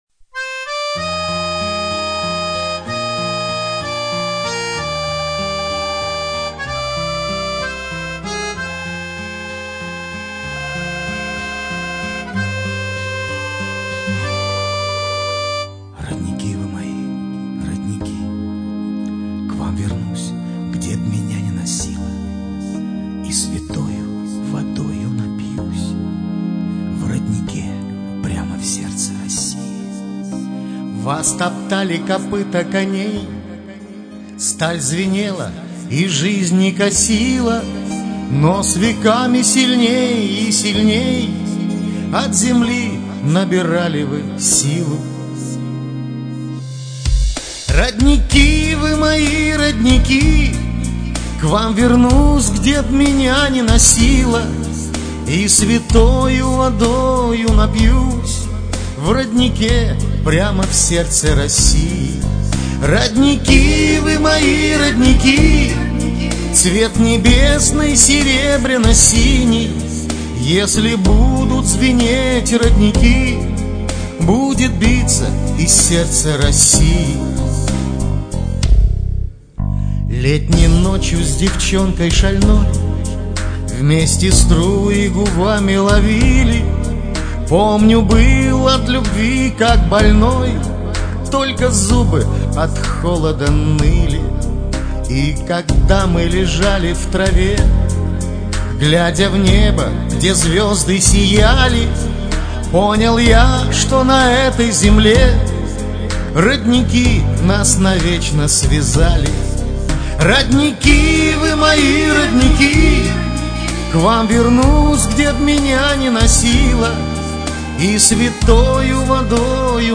Мужской (на мой взгляд) интереснее чем в оригинале!